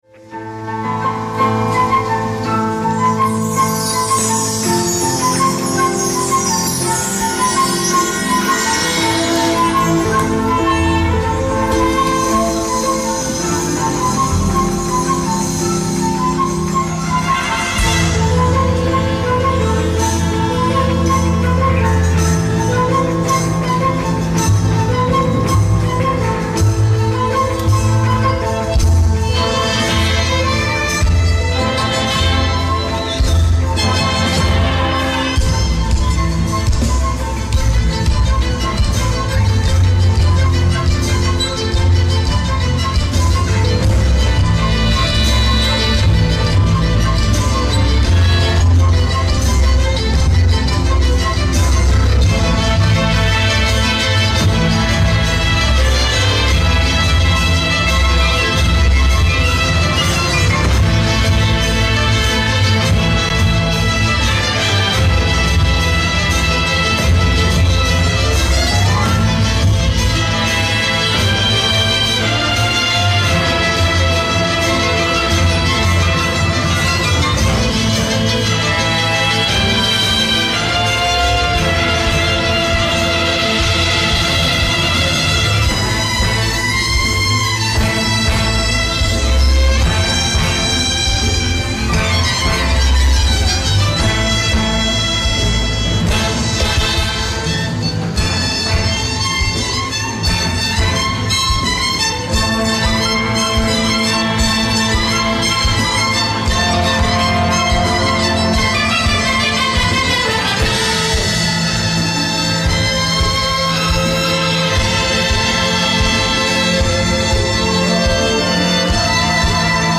DOWNLOAD THIS INSTRUMENTAL MP3